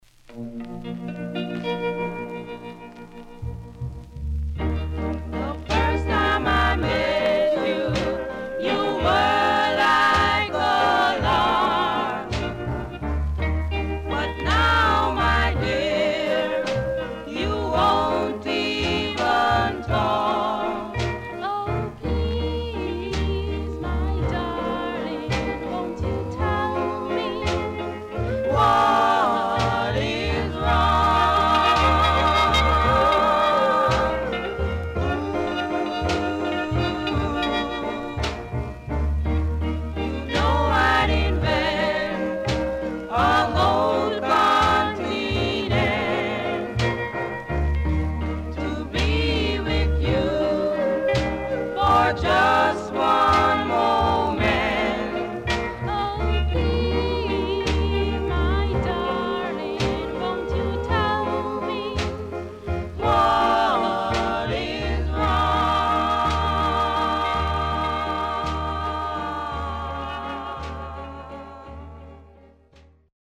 HOME > Back Order [VINTAGE 7inch]  >  SKA  >  BALLAD
SIDE A:かるいヒスノイズ入ります。